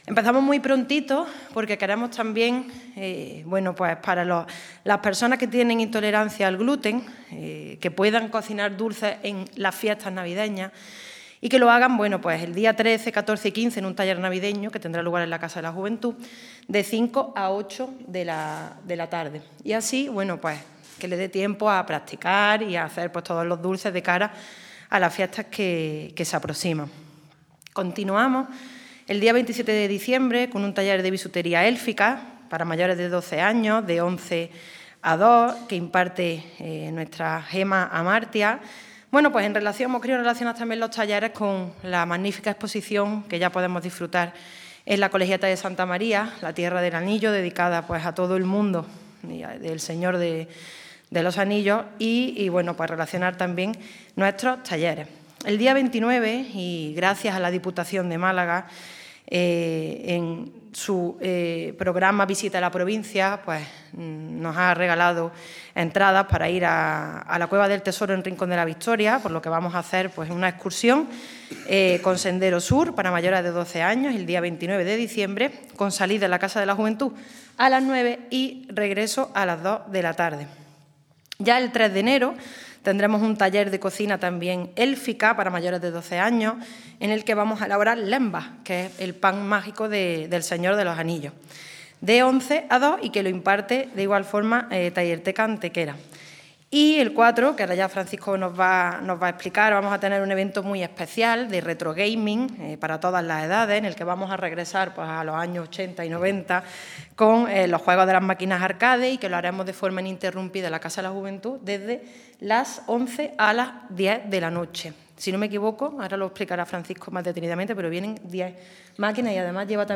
La teniente de alcalde delegada de Juventud, Elena Melero, ha presentado hoy en rueda de prensa el programa de actividades que el Área de Juventud tiene previsto desarrollar durante la Navidad en nuestra ciudad, alternando para ello videojuegos, cocina, bisutería o incluso una excursión.
Cortes de voz